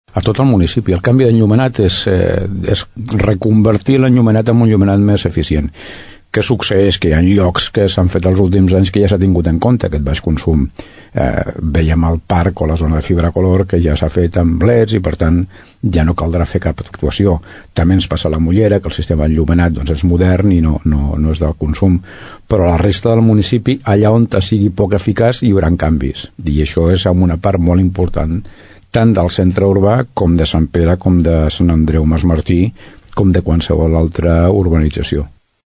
L’alcalde de Tordera, Joan Carles Garcia diu que aquesta millora de l’enllumenat es farà a tot el municipi.
alcalde-enllumenat.mp3